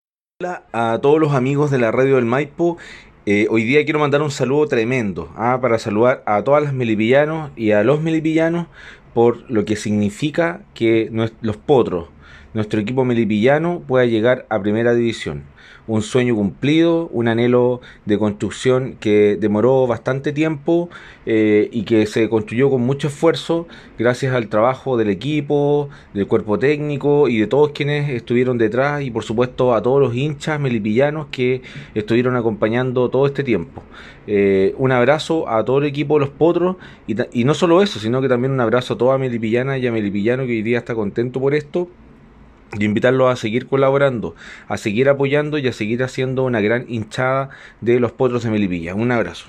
Por su parte, autoridades y candidatos para las elecciones de abril próximo quisieron entregar su saludo al programa radial Entre Portales: